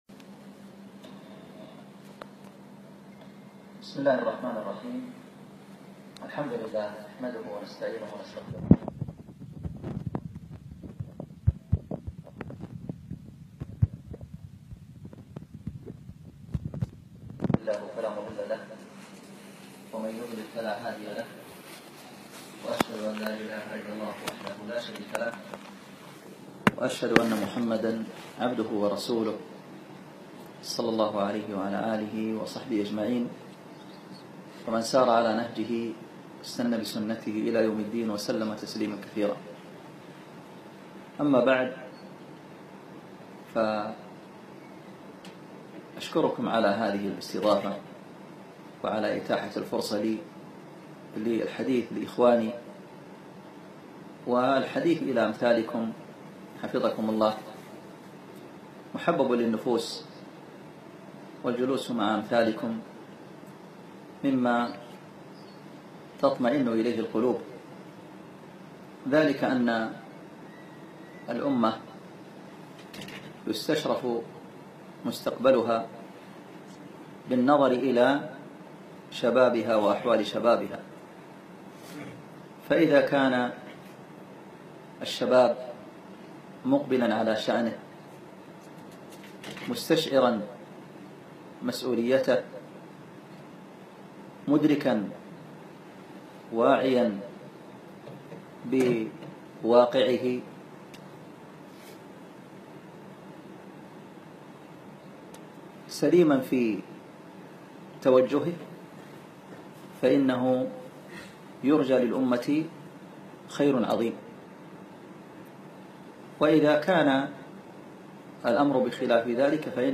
كلمة في استراحة مشروع الهداية